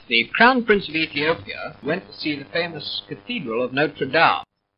And there, sure enough, we find Notre Dame with GOAT, e.g. in this 1932 newsreel about the then Crown Prince Of Ethiopia:
I wouldn’t recommend this pronunciation to those aiming at an SSB accent today.
notre_dame_pathe_1932.mp3